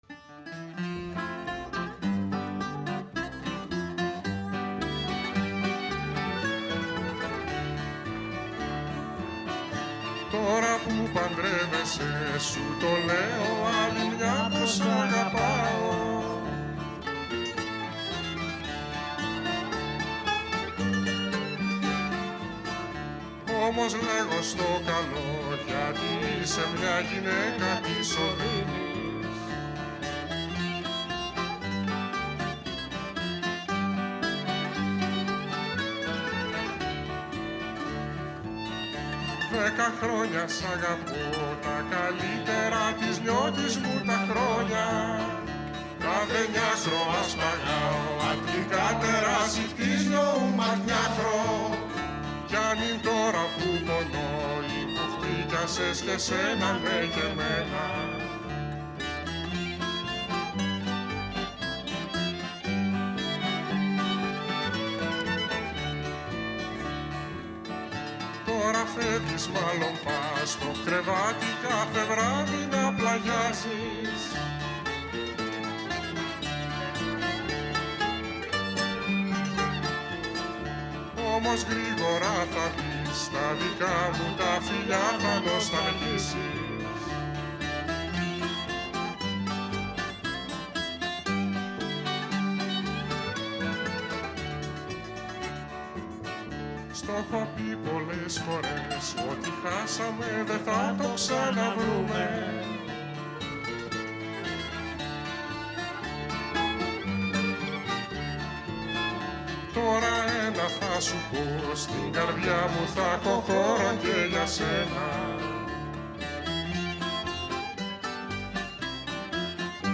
η συνέντευξη πραγματοποιήθηκε την Τετάρτη 22 Μαρτίου 2023 εκπομπή “Καλημέρα”, στον 9,58fm της ΕΡΤ3
Συνέντευξη με τον Αργύρη Μπακιρτζή για τις συναυλίες των Χειμερινών Κολυμβητών αυτό το Σαββατοκύριακο στη Θεσσαλονίκη, 25 & 26 Μαρτίου στη Θεσσαλονίκη, στο Κινηματοθέατρο Μακεδονικόν